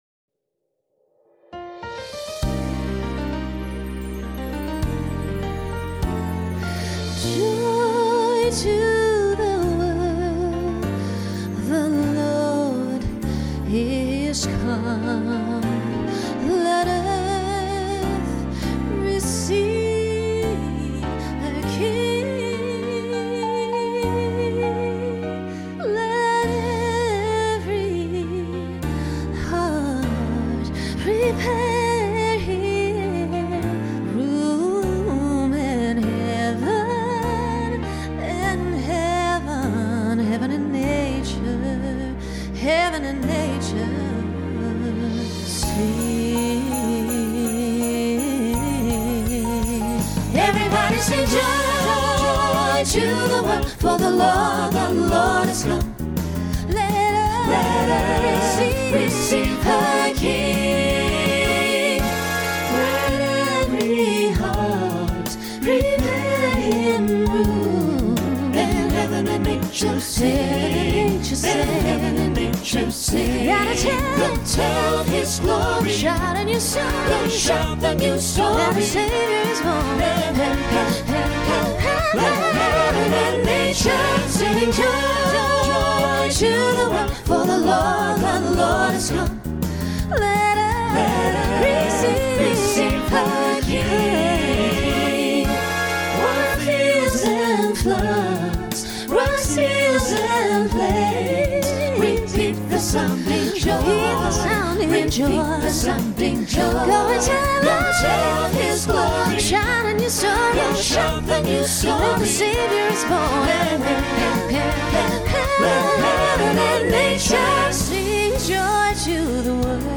Voicing SATB Instrumental combo Genre Holiday , Pop/Dance